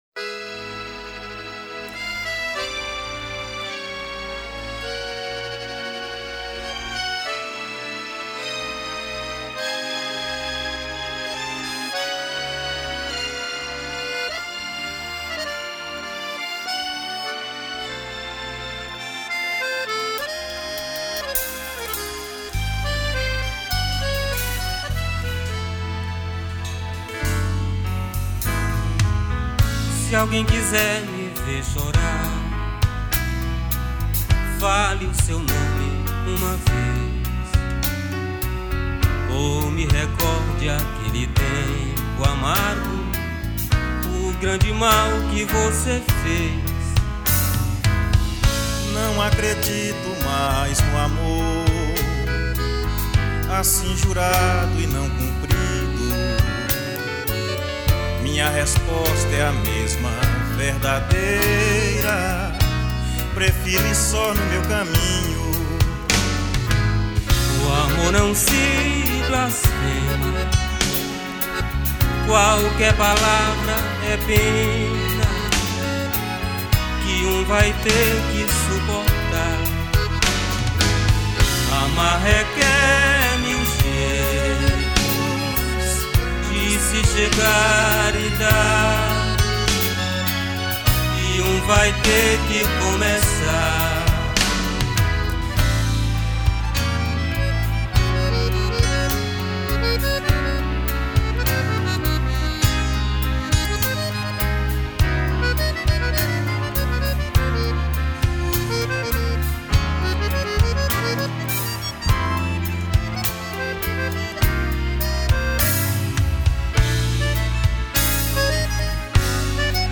Baixo Elétrico 6, Violao Acústico 6
Bateria
Teclados